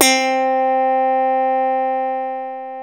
KW FUNK  C 4.wav